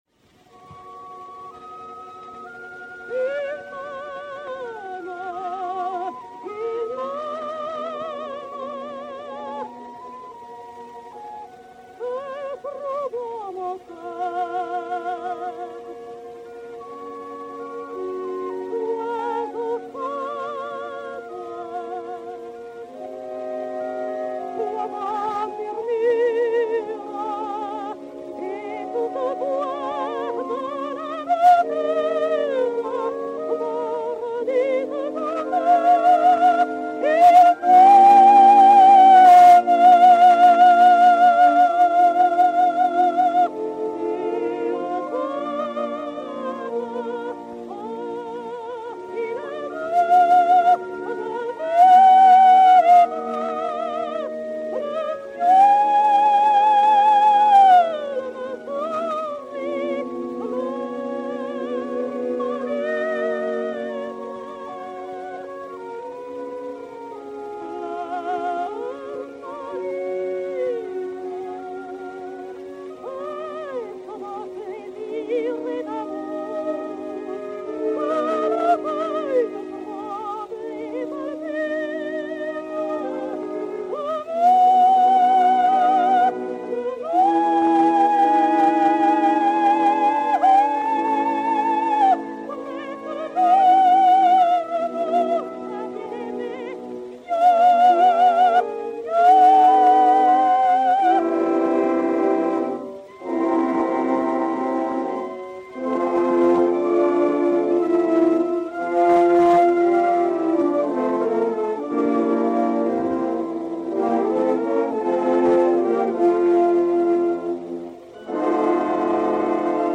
soprano suédois
Sigrid Arnoldson (Marguerite) et Orchestre
Disque Pour Gramophone 33705, mat. 12486u, enr. à Berlin en mars 1908